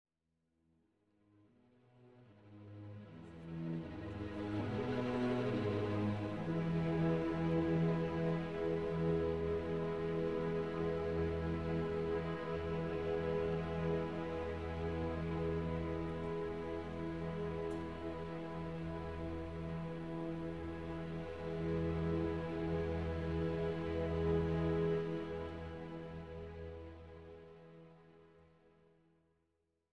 Classical and Opera